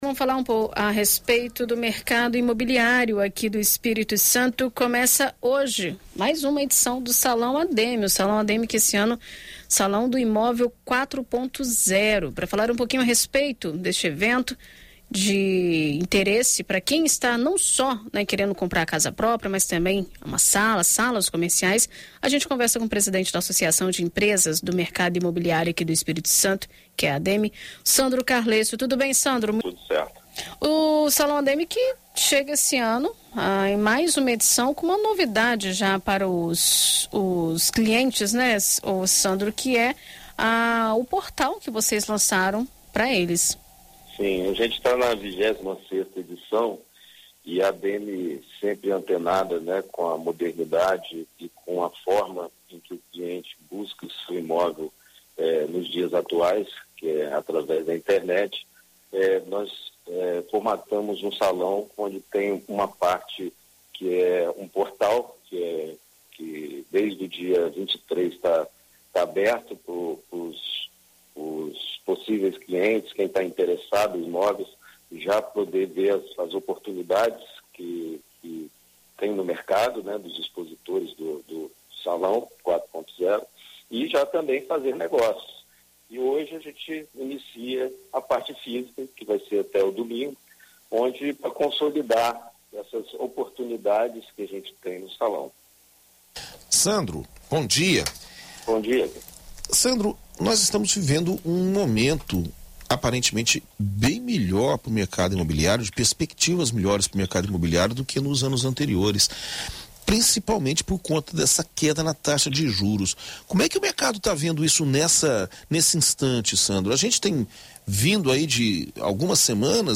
Em entrevista a BandNews FM Espírito Santo